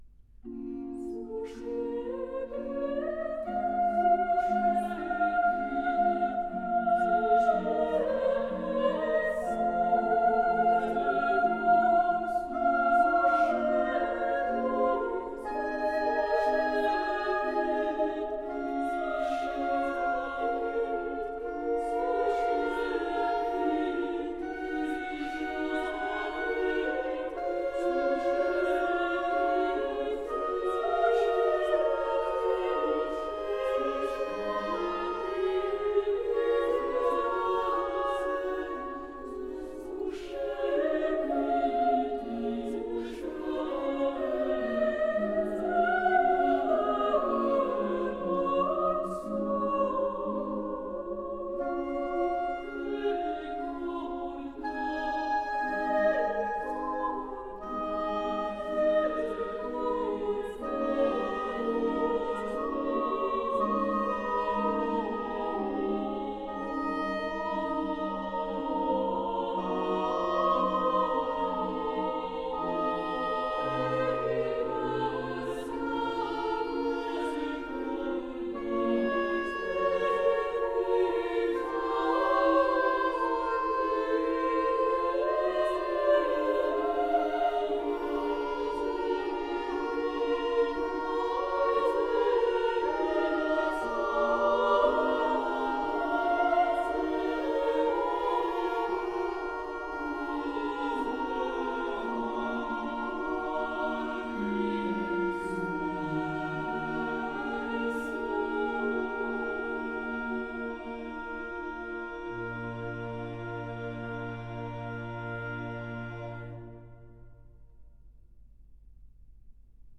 Suscepit Israel: Soprano I, II, Alto